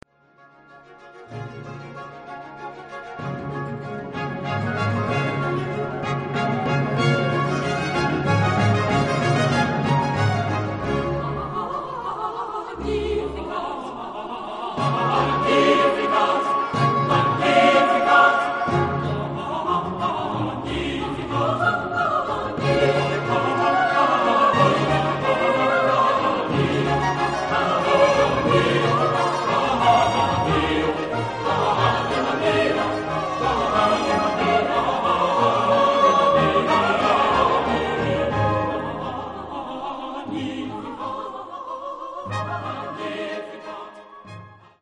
Genre-Stil-Form: geistlich ; Kantate
Charakter des Stückes: fröhlich
Chorgattung: SATB  (4 gemischter Chor Stimmen )
Solisten: Sopranos (2) / Alto (1) / Ténor (1) / Basse (1)  (5 Solist(en))
Instrumentation: Orchester  (13 Instrumentalstimme(n))
Instrumente: Trompete (3) ; Pauke (1) ; Flöte (2) ; Oboe (2) ; Violinen (2) ; Viola (1) ; Violoncello (1) ; Basso Continuo
Tonart(en): D-Dur
von Dresdner Kammerchor gesungen unter der Leitung von Hans-Christoph Rademann